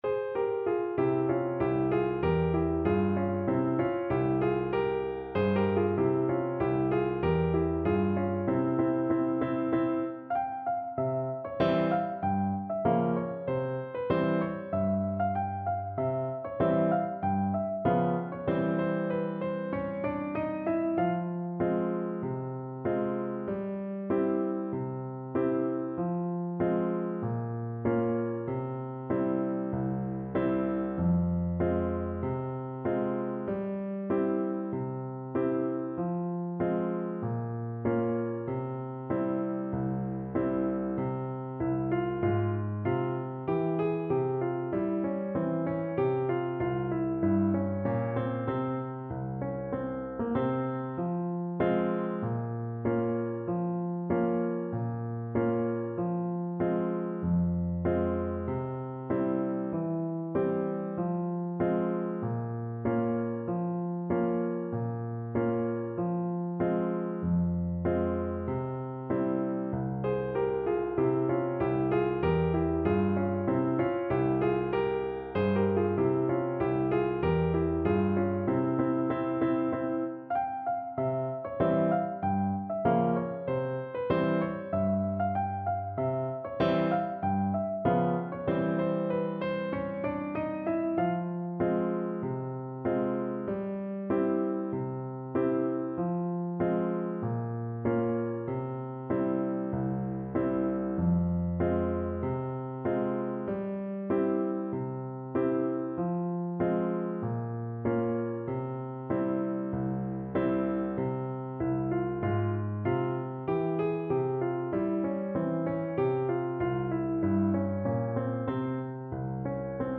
Slow =c.96
Clarinet pieces in C minor